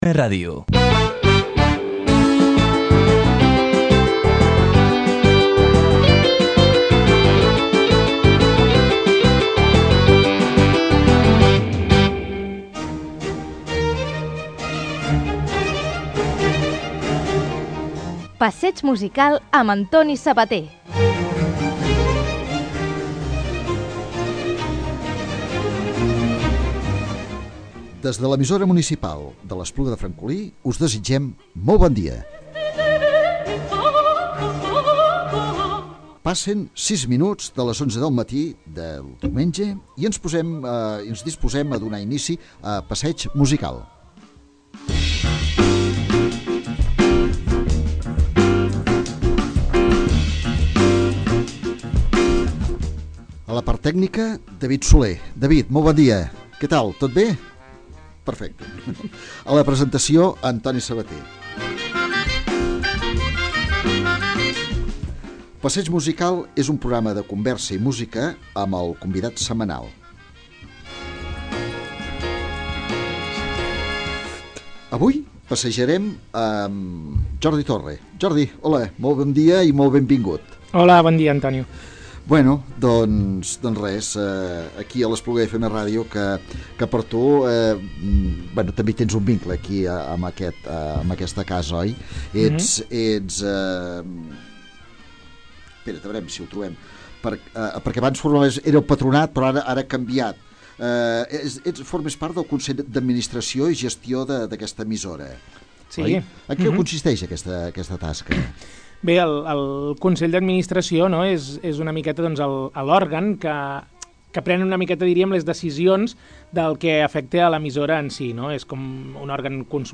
Tot i els càrrecs polítics, hem preferit parlar de coses més importants, per això hem gaudit de la música que ens ha proposat i hem recordat vivències a l’Agrupament Escolta i de la Sínia, on hi va fer de cambrer i discjòquei.